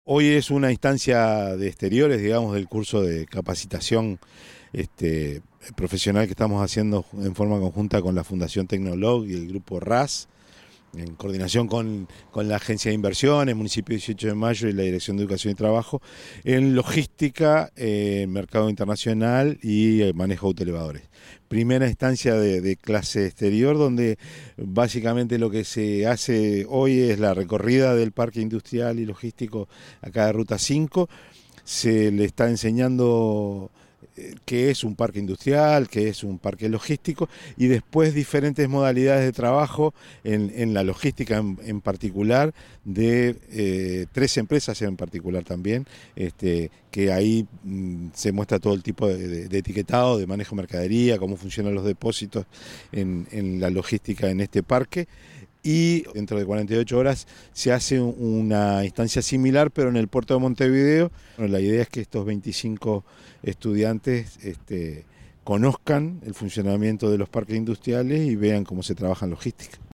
El Director de Educación y Trabajo de la Intendencia de Canelones, Lic. Jorge Repetto, presente en la recorrida informó que “la participación al curso viene siendo espectacular.